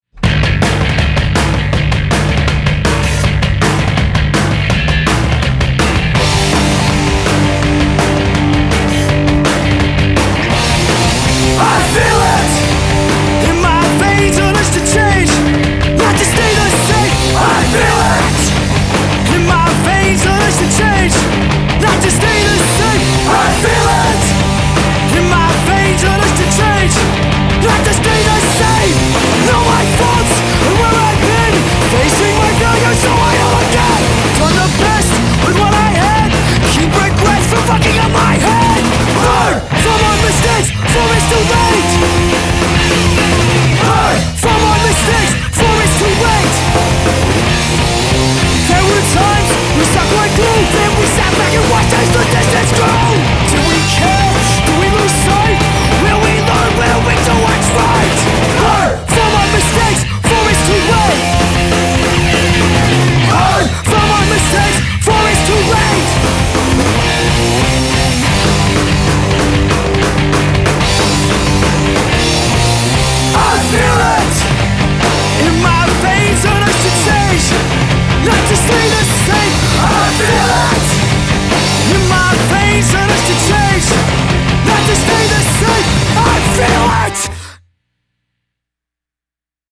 Comments: vocal so good
Always five, because the are my favorite sxe hard-core band!
Comments: nice raw edge to it.